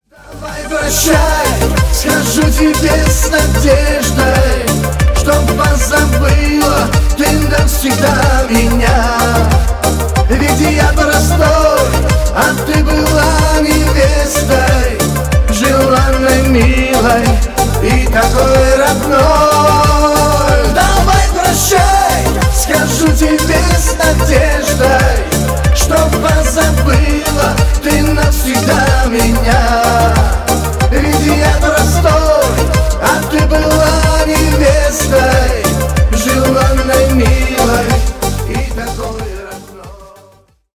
Поп Музыка
кавказские # грустные